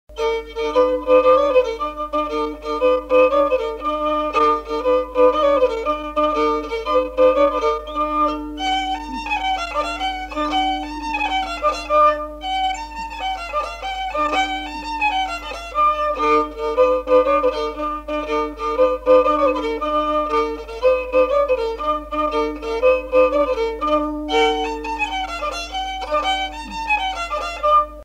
Rondeau
Aire culturelle : Lomagne
Lieu : Garganvillar
Genre : morceau instrumental
Descripteurs : rondeau
Instrument de musique : violon